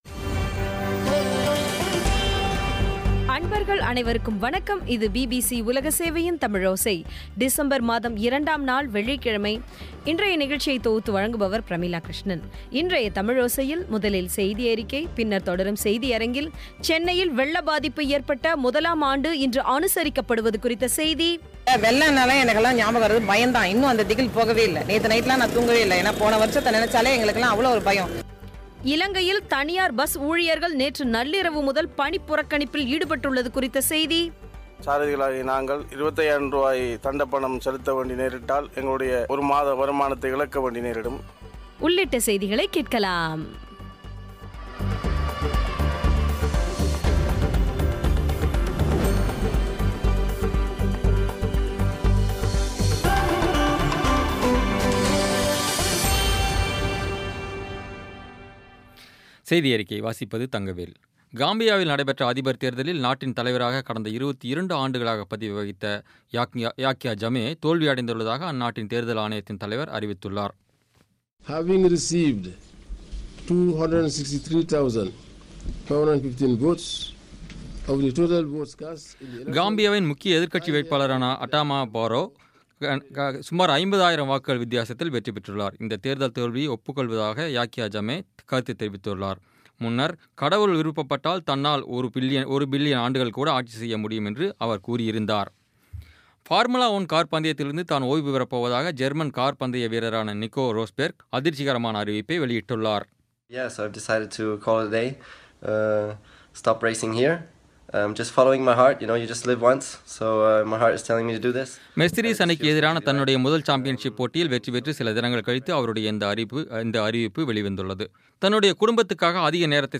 இன்றைய தமிழோசையில், முதலில் செய்தியறிக்கை, பின்னர் தொடரும் செய்தியரங்கத்தில், சென்னையில் வெள்ளப்பாதிப்பு ஏற்பட்ட முதலாம் ஆண்டு இன்று அனுசரிக்கப்படுவது குறித்த செய்தி இலங்கையில் தனியார் பஸ் ஊழியர்கள் நேற்று நள்ளிரவு முதல் பணிப்புறக்கணிப்பில் ஈடுபட்டுள்ளது குறித்த செய்தி உள்ளிட்ட செயதிகளைக் கேட்கலாம்.